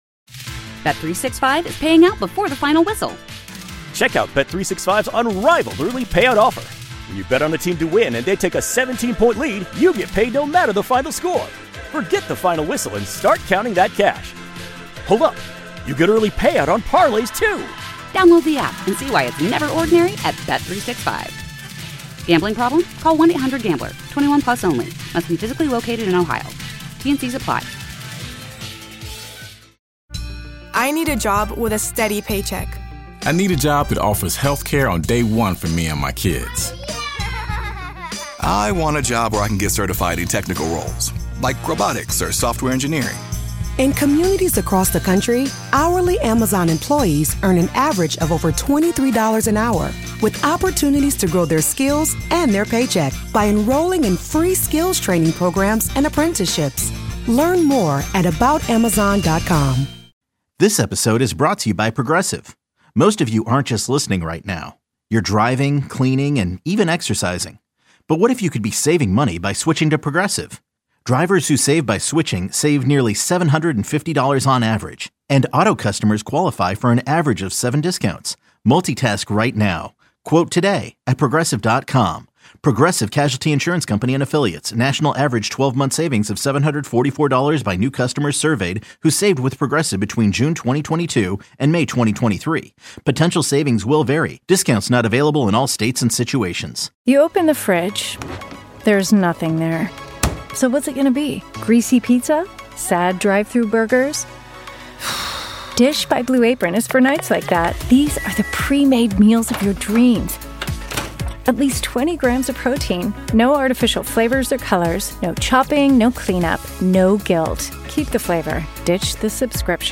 THE EXTRA POINT is designed for full engagement with bills fans as the two hosts open the phones, texts sand tweets to connect with the Bills mafia on everything Bills!